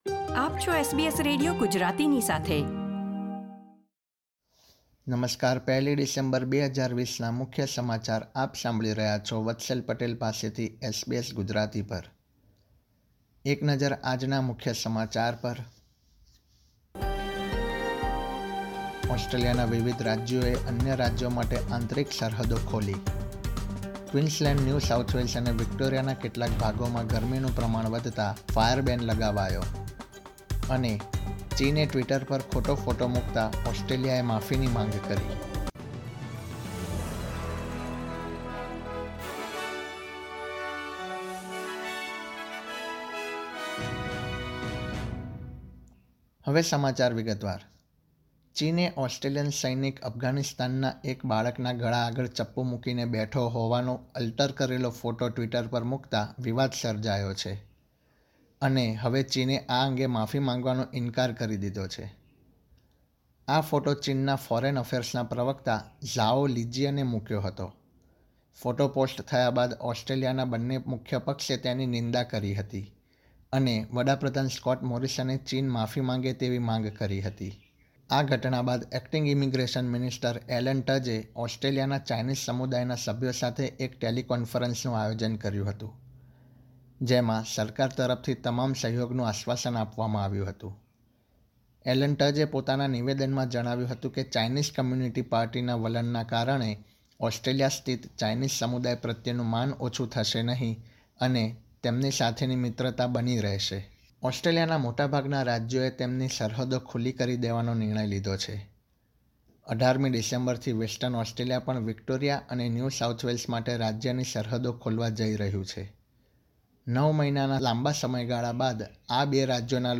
SBS Gujarati News Bulletin 1 December 2020
gujarati_0112_newsbulletin.mp3